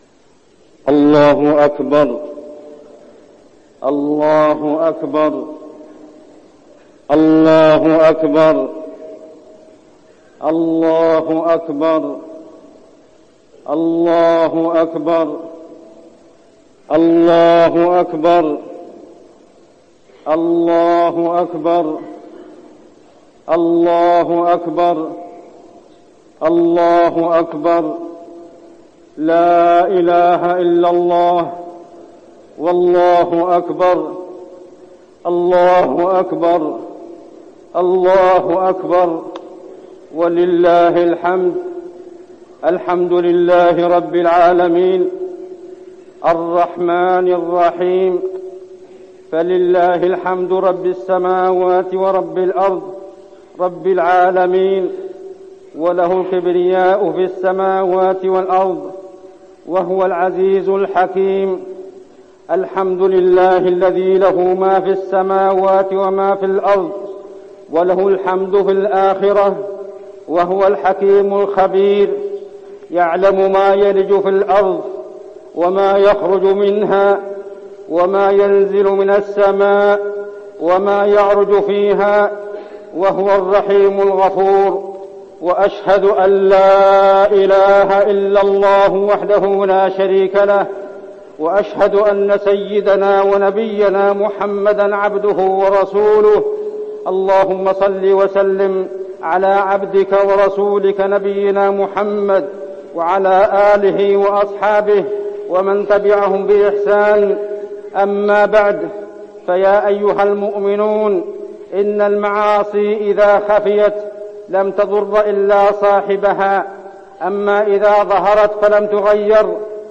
خطبة الاستسقاء - المدينة- الشيخ عبدالله الزاحم - الموقع الرسمي لرئاسة الشؤون الدينية بالمسجد النبوي والمسجد الحرام
تاريخ النشر ١٩ ربيع الثاني ١٤٠٩ هـ المكان: المسجد النبوي الشيخ: عبدالله بن محمد الزاحم عبدالله بن محمد الزاحم خطبة الاستسقاء - المدينة- الشيخ عبدالله الزاحم